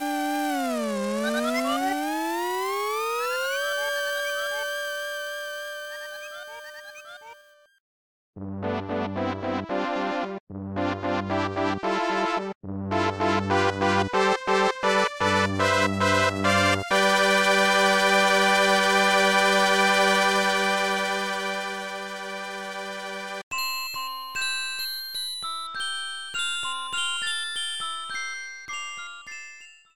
Fair use music sample
Clipped to 30 seconds and applied fade-out.